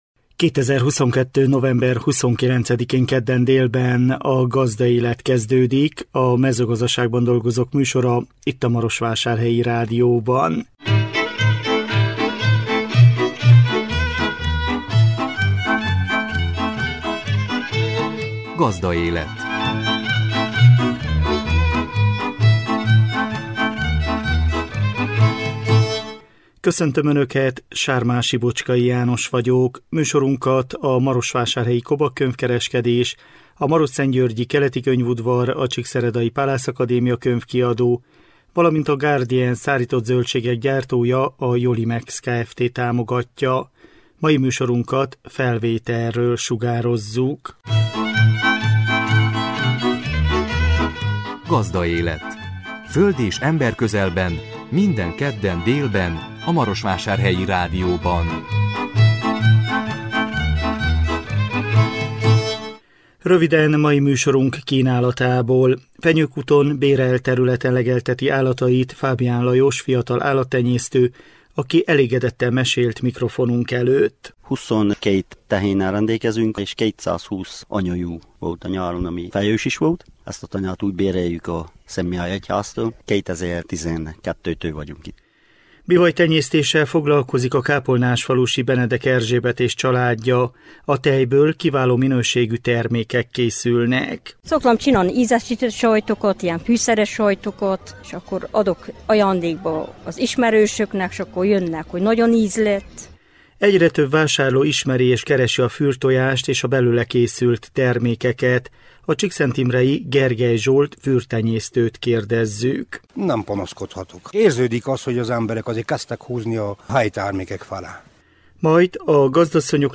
interjúnk